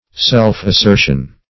Self-assertion \Self`-as*ser"tion\, n.